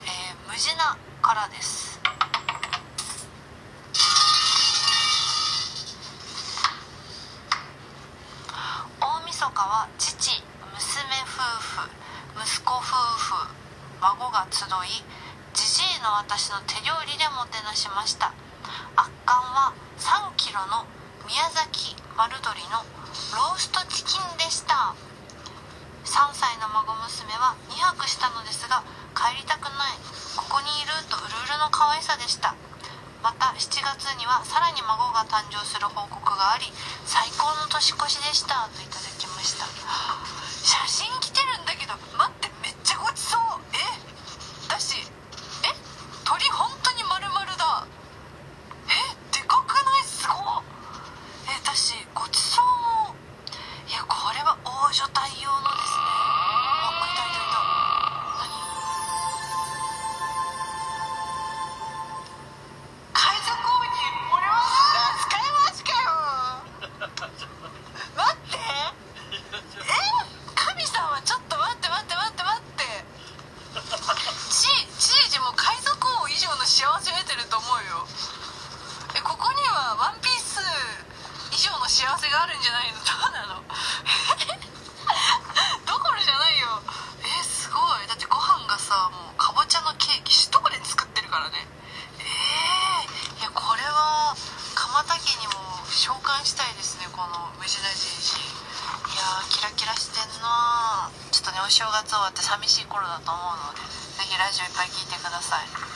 年初は収録も多いラジコ番組で鎌田菜月のサクラバシ９１９は生放送。